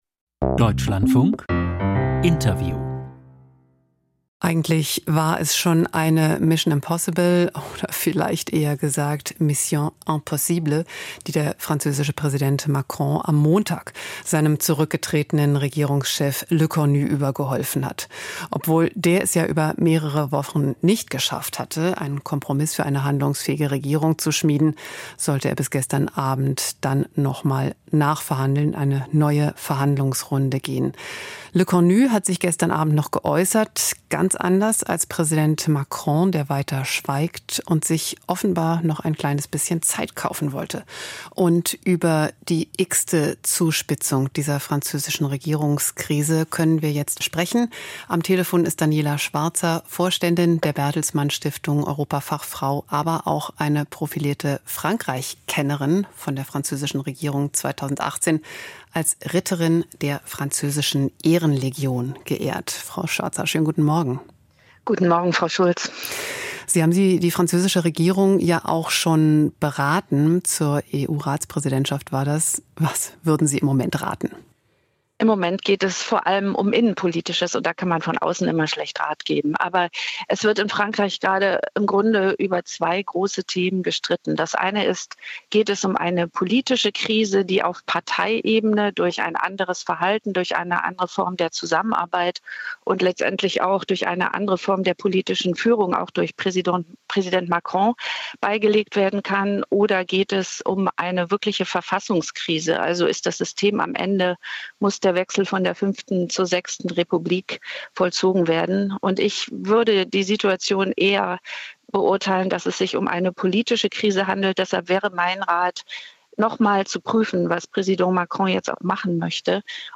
Frankreichs Regierungskrise - Interview